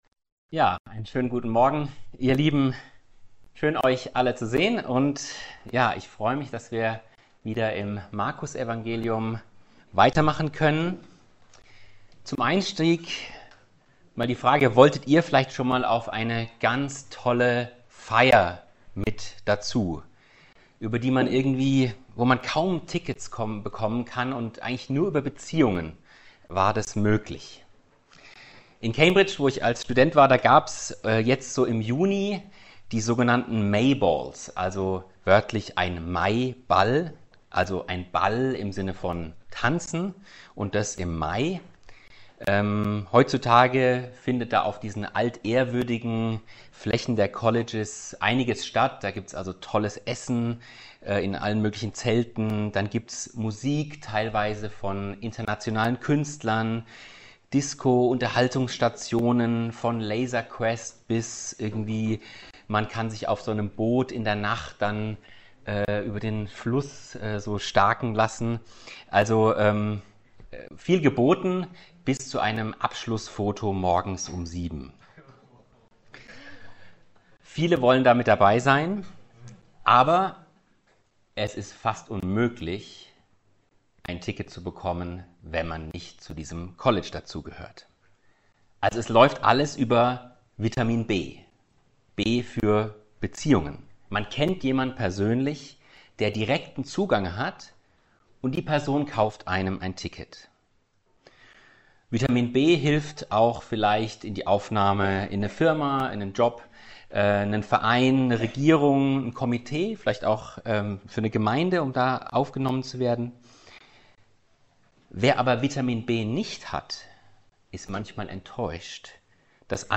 Predigtreihe: Markusevangelium